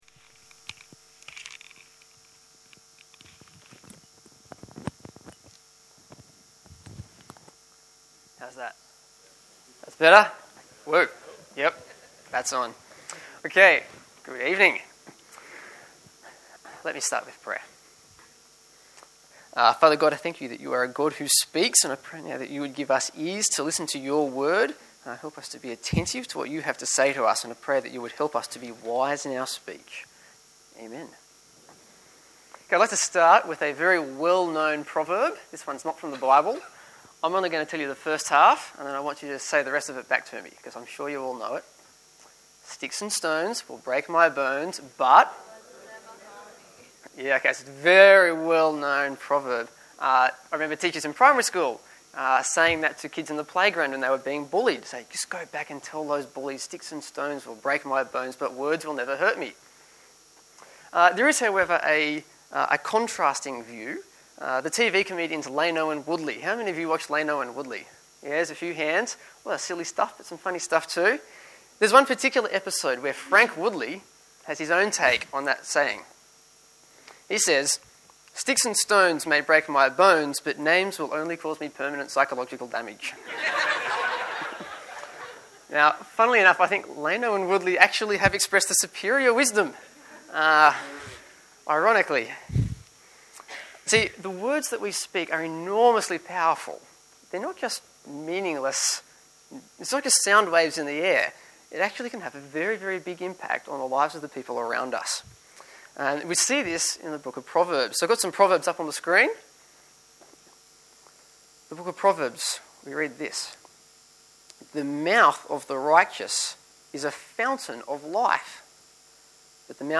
View Sermon details and listen